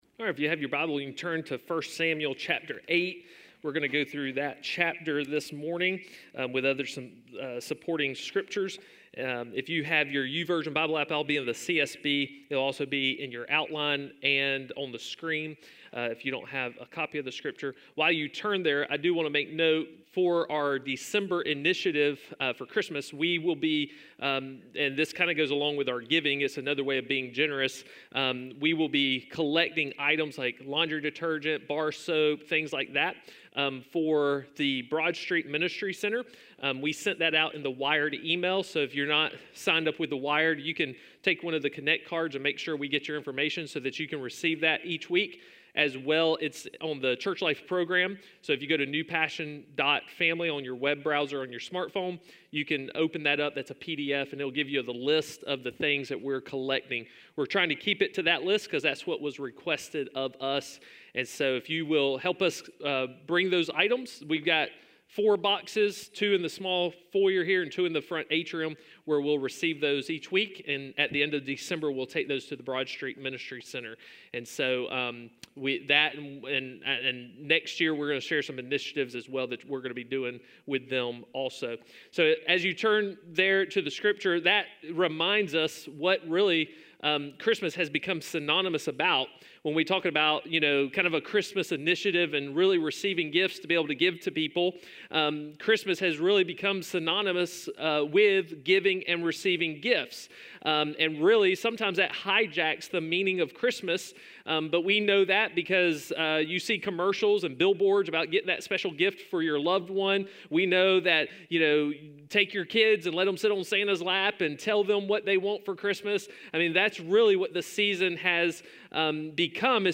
A message from the series "X-Files." If you feel insignificant and unworthy to God, the Christmas story reveals just how significant we are to God and how Jesus is the gift of hope to everyone who trusts in Him.